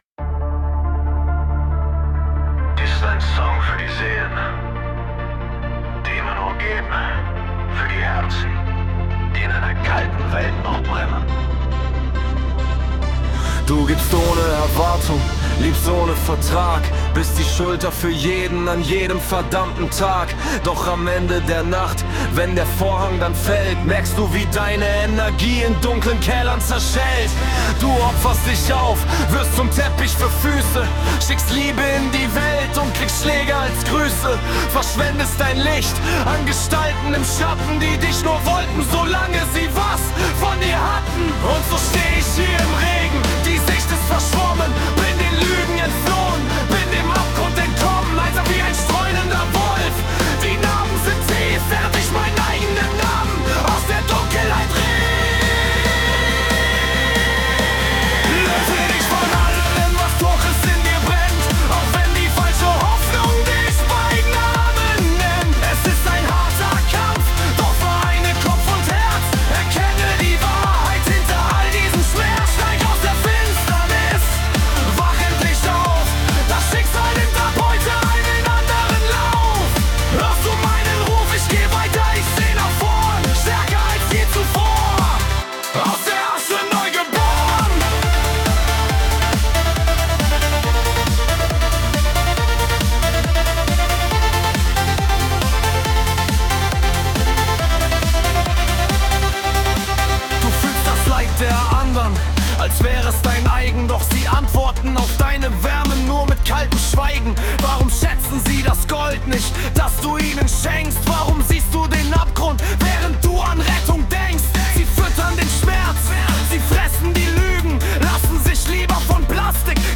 Techno Version